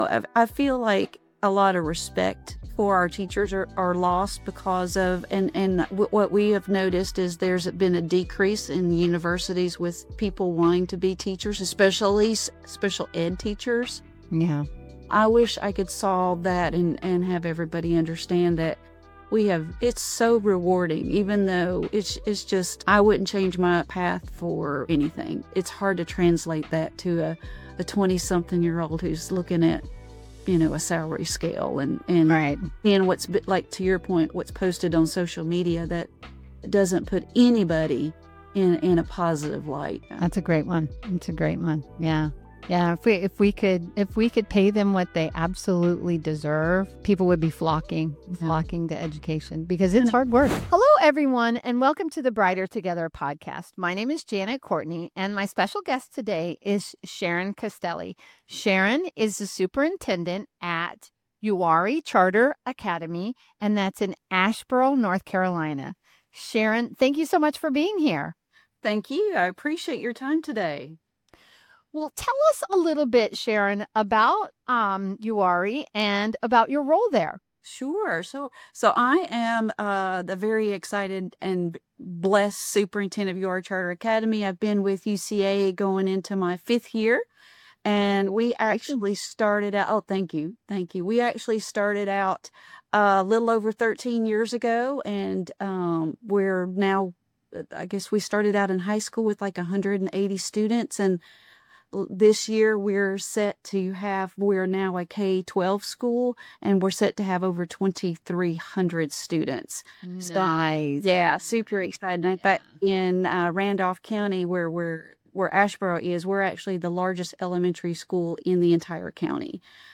This is a must-hear conversation for educational leaders ready to move from surface-level change to sustainable growth.